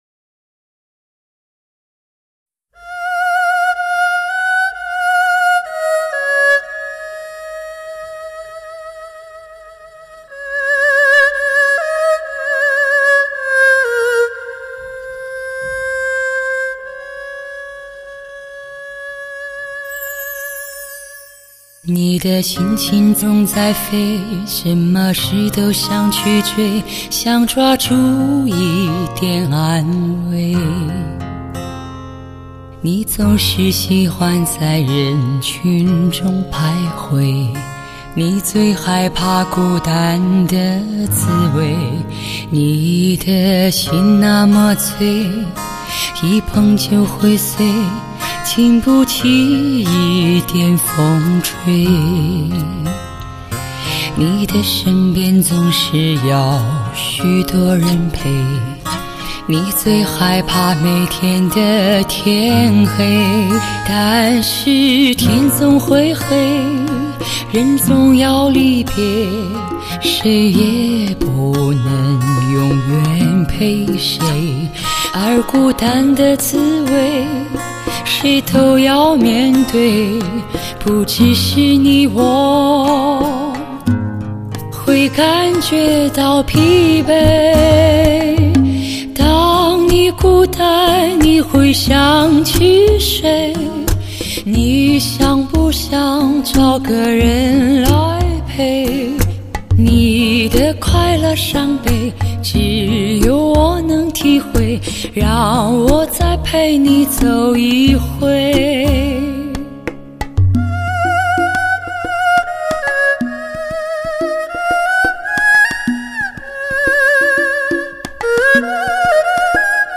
独特醇厚的嗓音独一无二    花样年华的仙境顶级发烧
如月光下绽放的罂粟花，美丽绝艳，仿佛自天外飘来，让人上瘾而欲罢不能。
绝对是梦寐以求的顶级发烧女声。